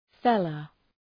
Προφορά
{‘felə}